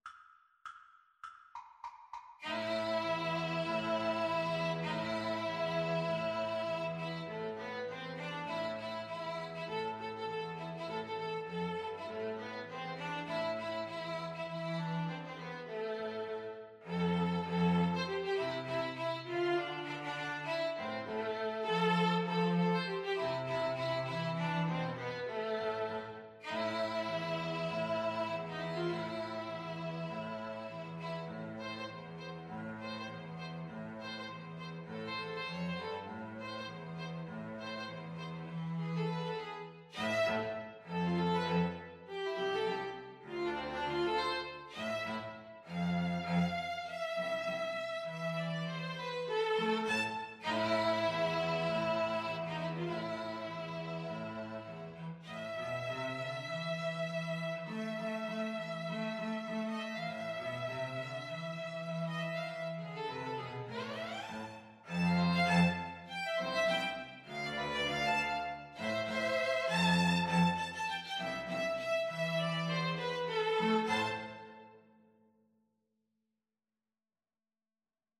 String trio version
Firmly, with a heart of oak! Swung = c.100